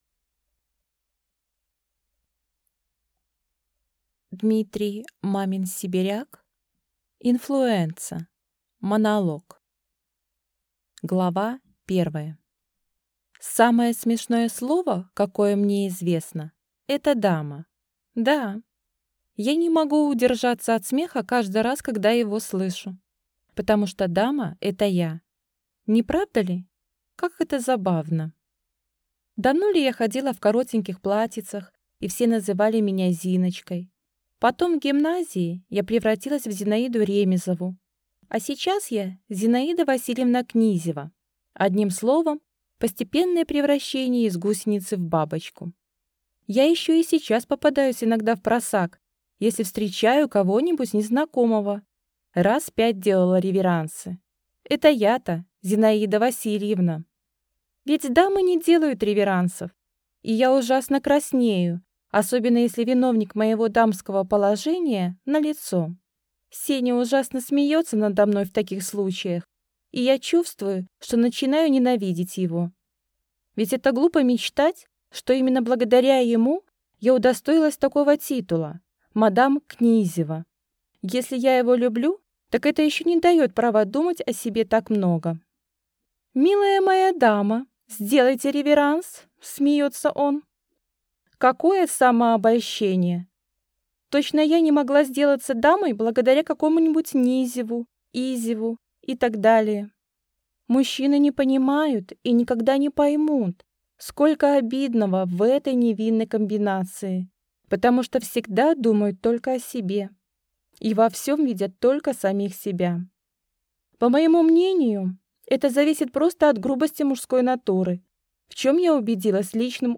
Аудиокнига Инфлуэнца | Библиотека аудиокниг
Прослушать и бесплатно скачать фрагмент аудиокниги